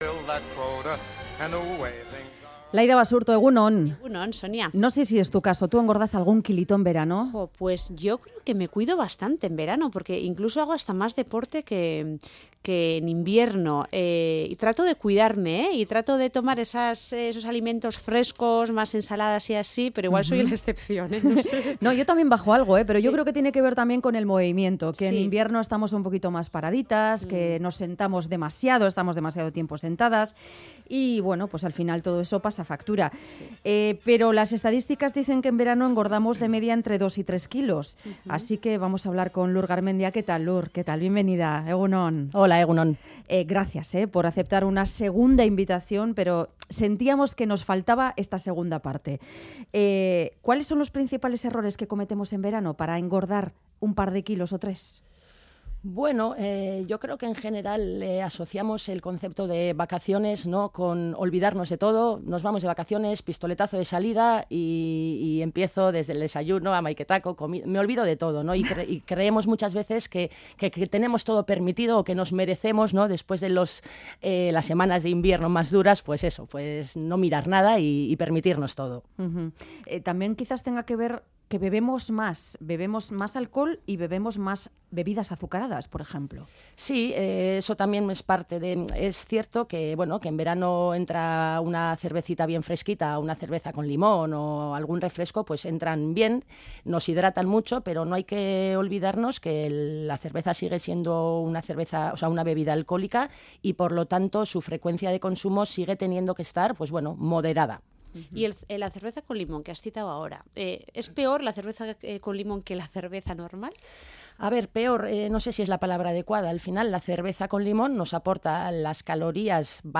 ha estado en el programa "Boulevard" de Radio Euskadi.